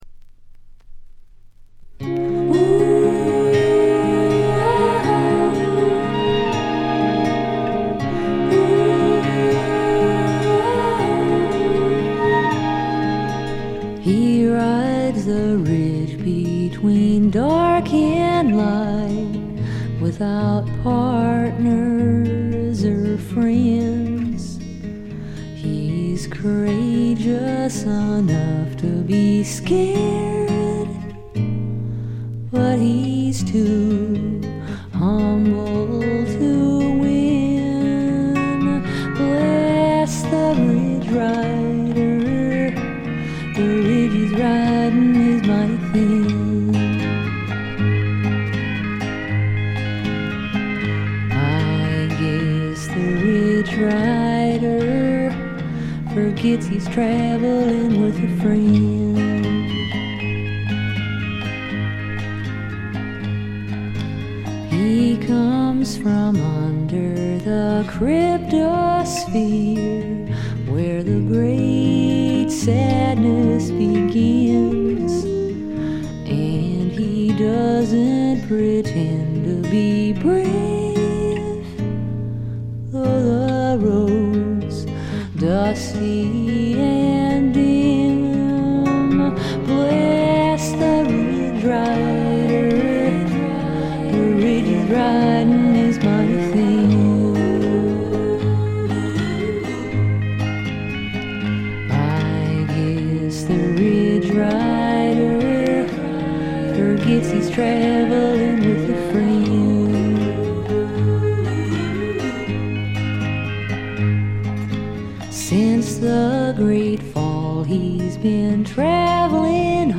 B1フェードアウト消え際から曲間部で軽微なチリプチ。
試聴曲は現品からの取り込み音源です。
曲間のチリプチもご確認ください。